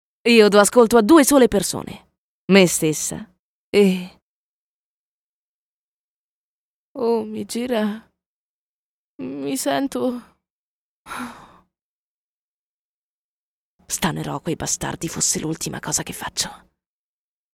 Sprecherin italienisch. Voce intensa, accattivante, particolarmente adatta a letture di tipo documentaristico, o anche di audiolibri. Lettura chiara e scorrevole.
Sprechprobe: eLearning (Muttersprache):